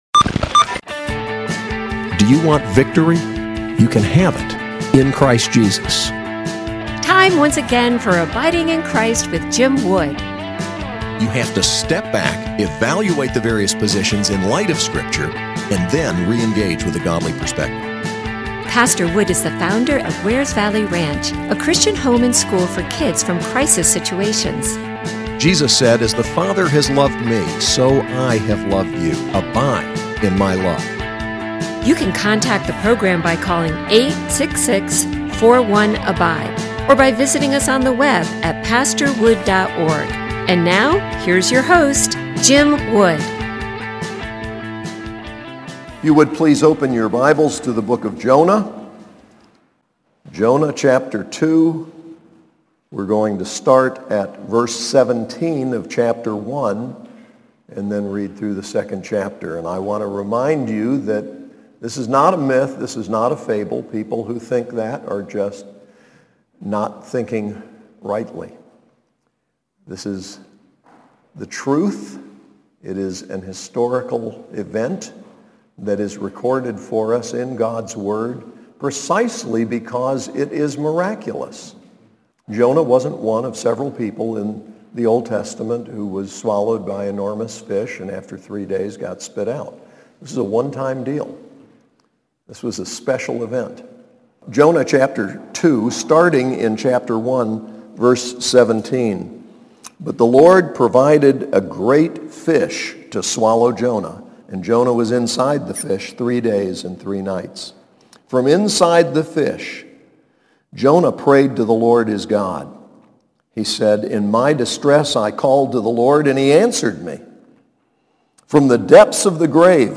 SAS Chapel: Jonah 2